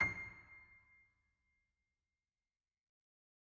piano-sounds-dev
SoftPiano
c6.mp3